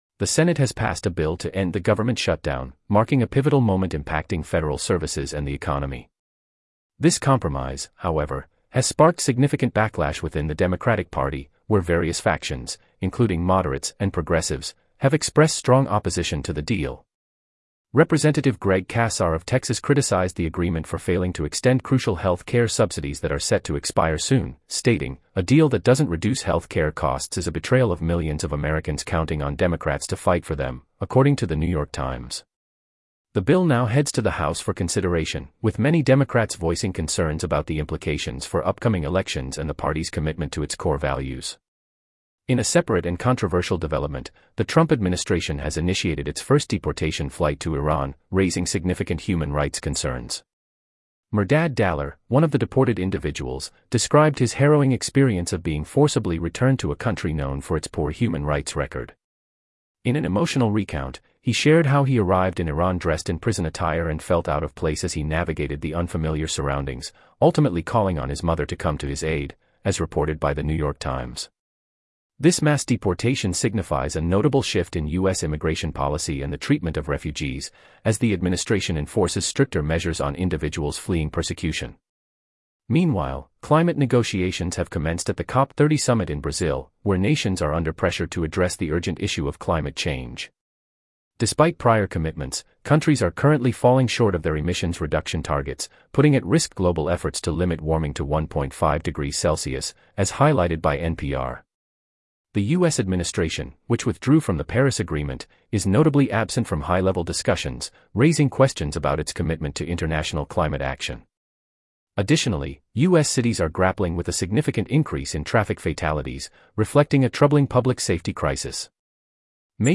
Top News Summary